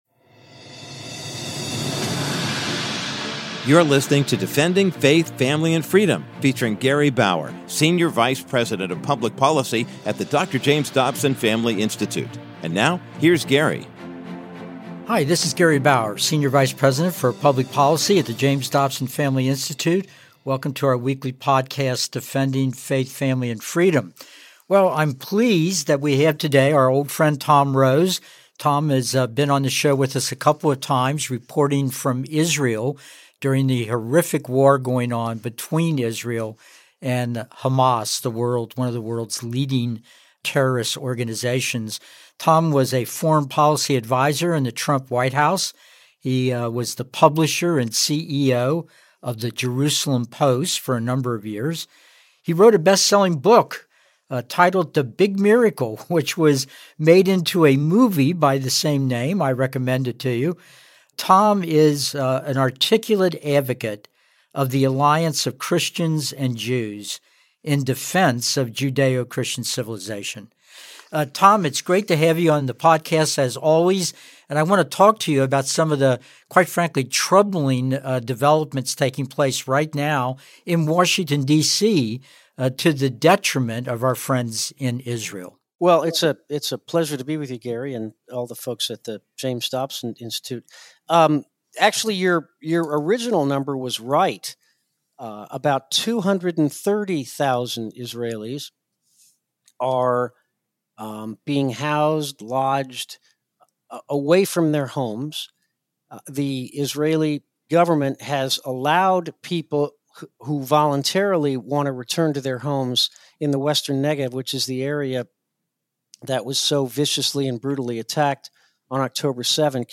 In this week's episode, Gary Bauer interviews special guest Tom Rose, who shares astonishing revelations recently discovered by the Israel Defense Forces (IDF) during its military operations in Gaza. The IDF has uncovered an extensive multi-billion dollar tunnel system, longer and more complex than the London subway system.